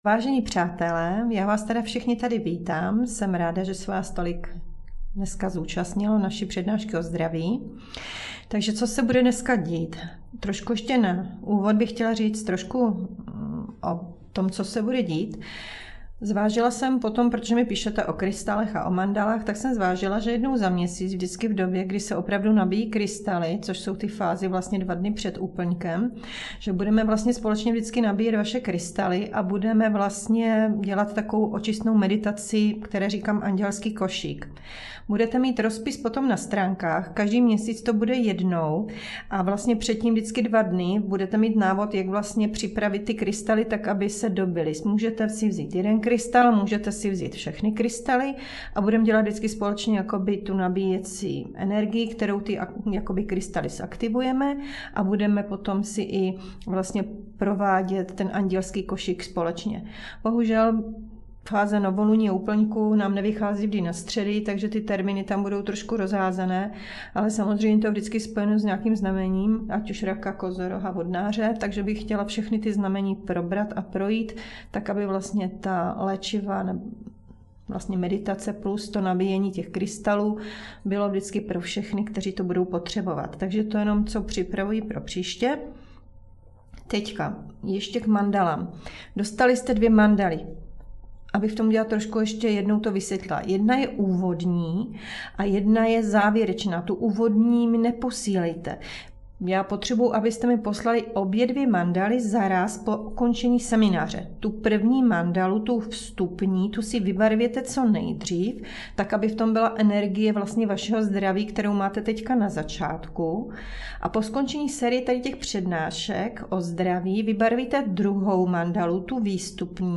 Přednáška Zdraví, díl 2. - Kořenová čakra